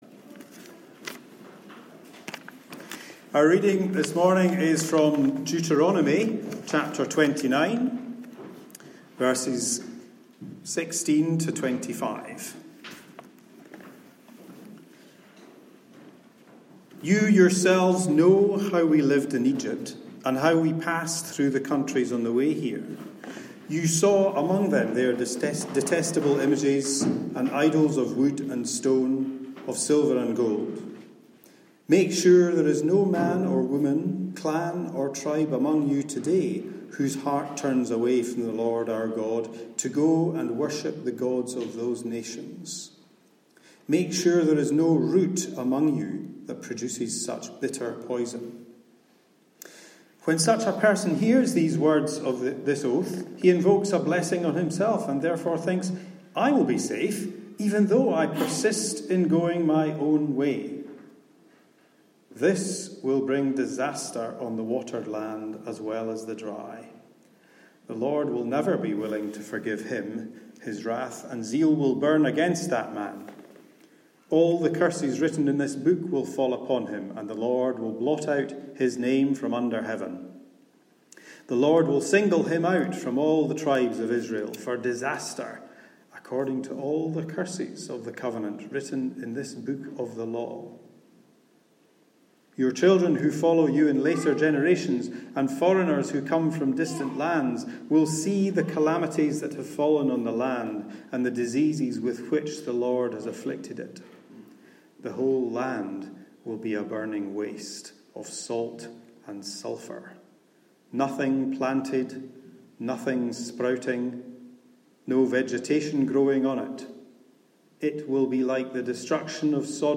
Message on Ecology and Environmental Issues- 21st July 2019